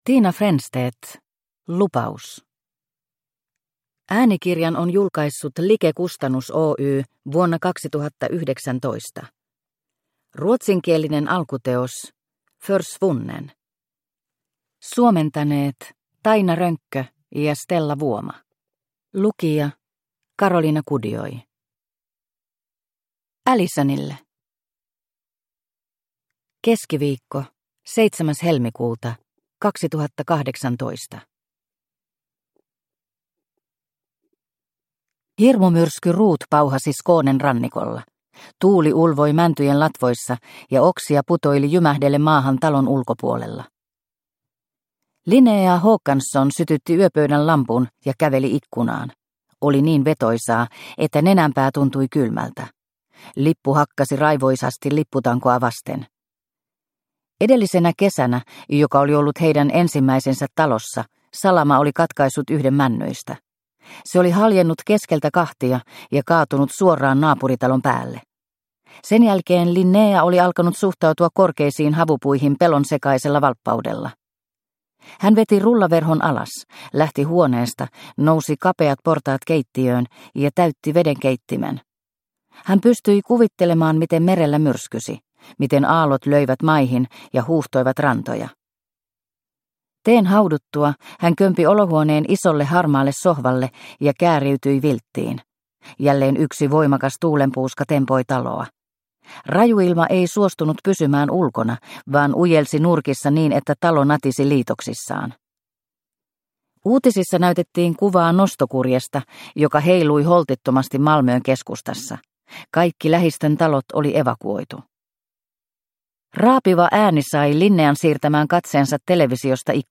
Lupaus – Ljudbok – Laddas ner